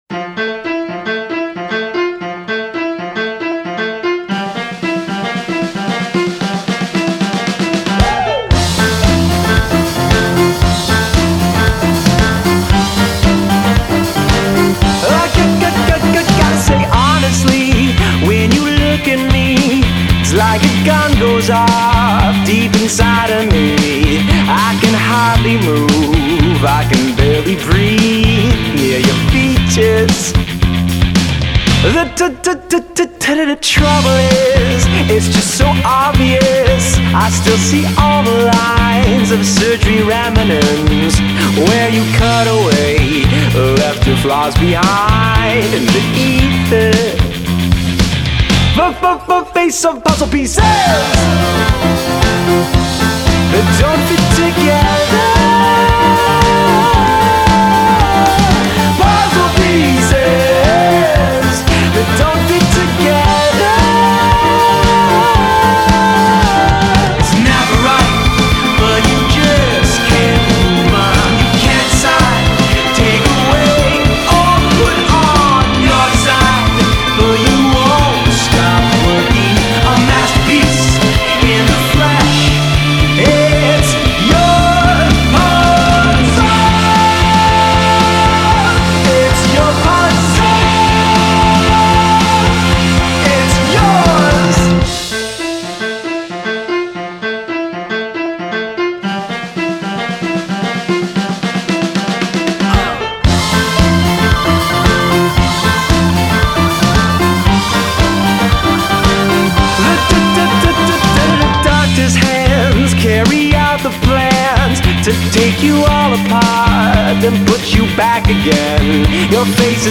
not only creates jaunty, danceable pop beats